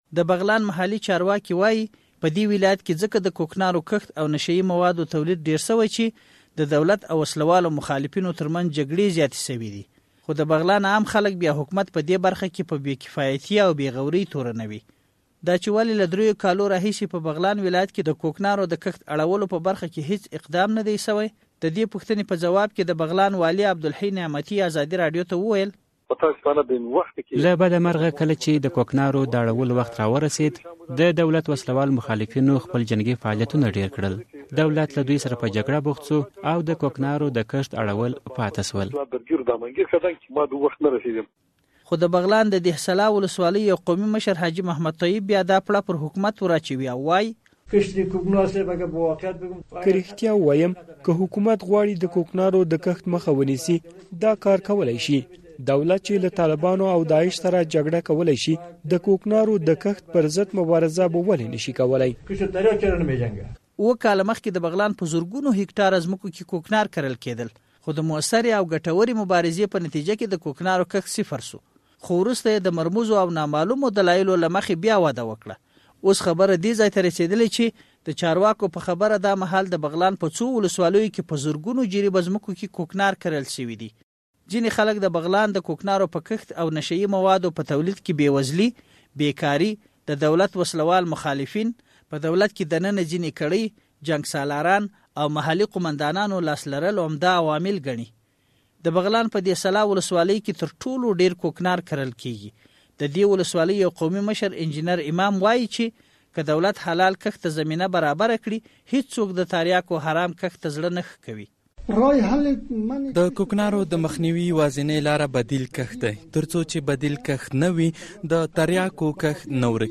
د بغلان راپور